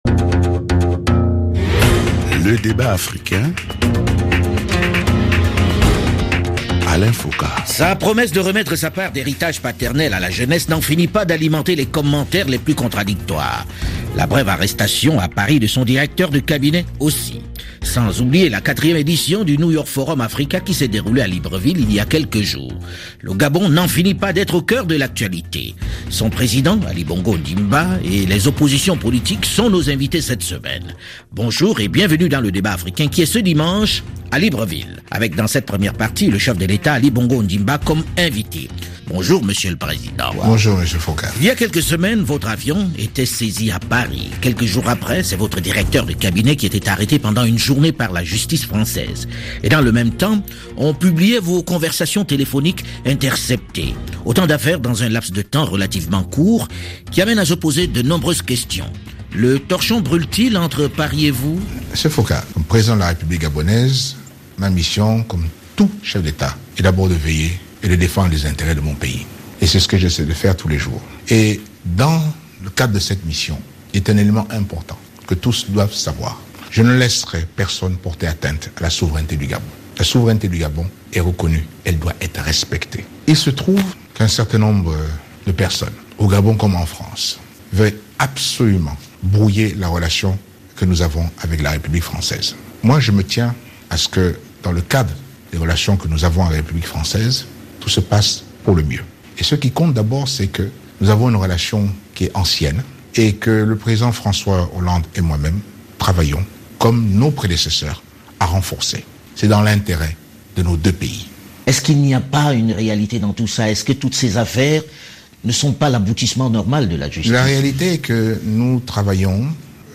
Remise de sa part d’héritage paternel, arrestation à Paris de son directeur de cabinet, 4ème édition du New York Forum Africa etc. Le Président gabonais Ali Bongo Ondimba répond ce dimanche aux questions du journaliste Alain Foka (RFI). Un entretien qui sera suivi d’un débat entre le porte-parole de la Présidence et une partie de l’opposition et de la société civile.